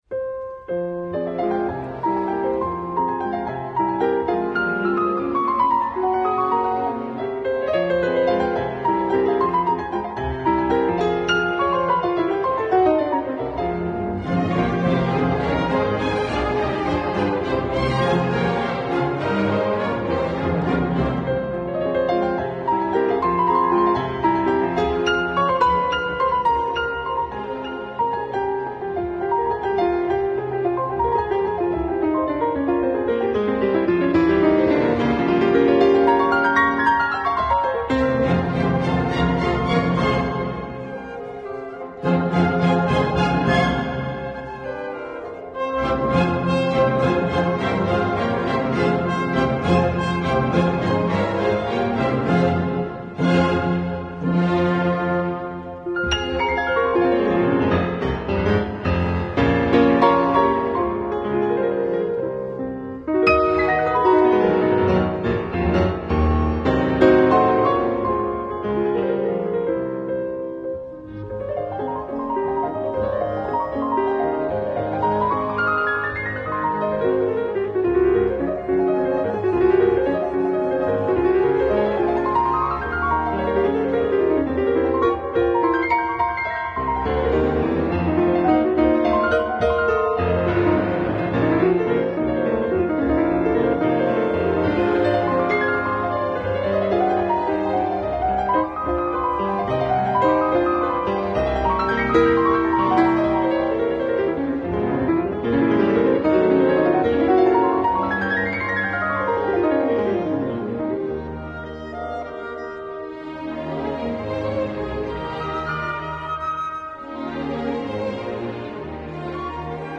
Young Ravens Sutro Heights Park,